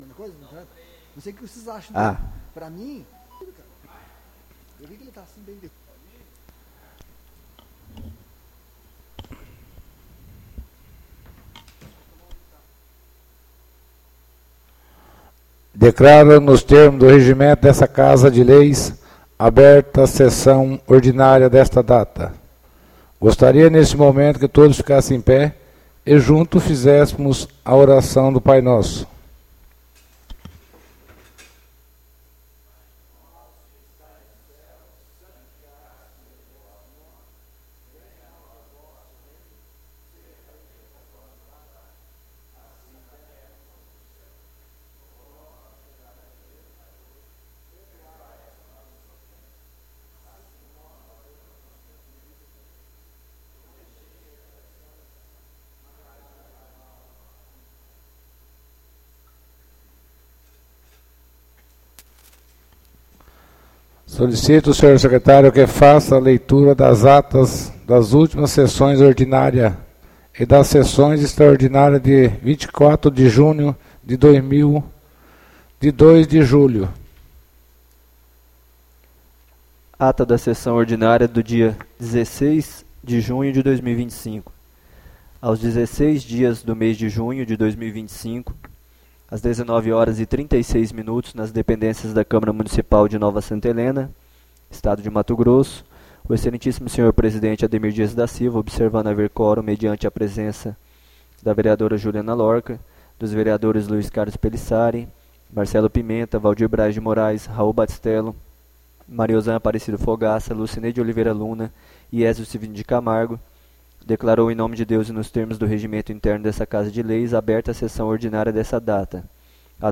ÁUDIO SESSÃO 07-07-25 — CÂMARA MUNICIPAL DE NOVA SANTA HELENA - MT
Sessões Plenárias